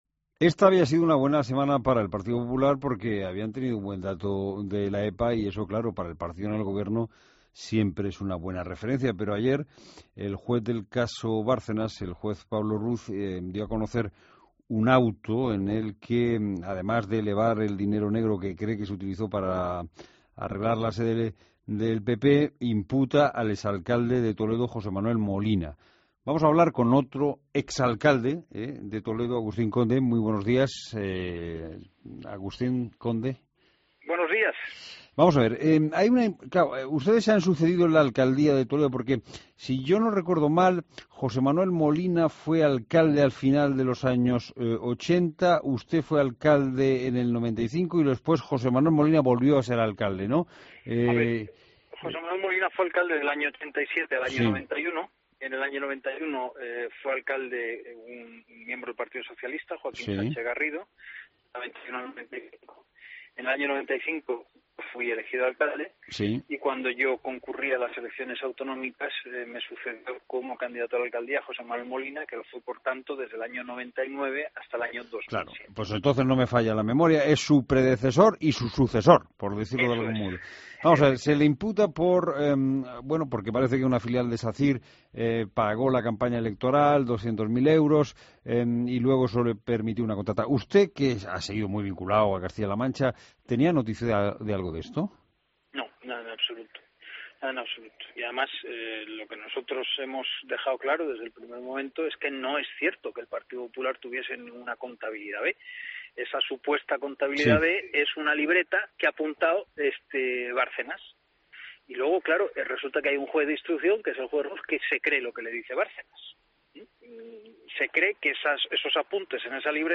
Entrevista a Agustín Conde en La Mañana Fin de Semana